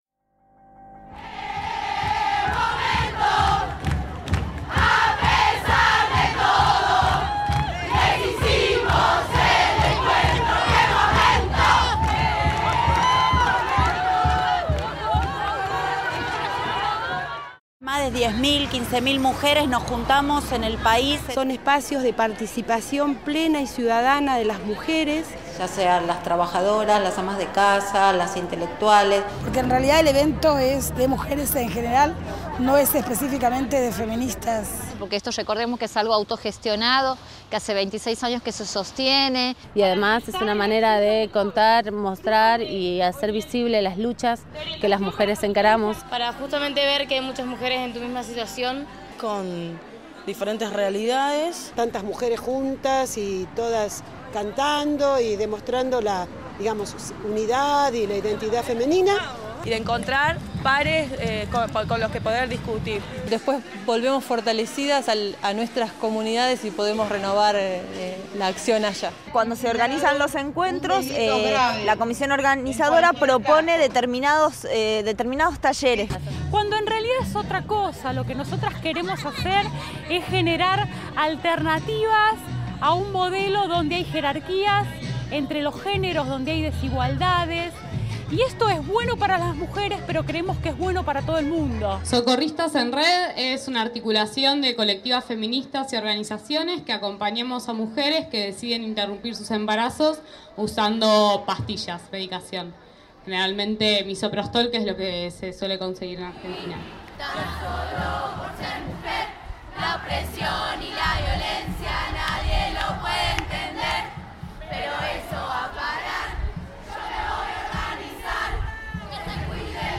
Especial 31 ENM | Micro voces de mujeres
Cobertura especial del 31 Encuentro Nacional de Mujeres realizado durante los días 8, 9 y 10 de octubre en Rosario, Santa Fe.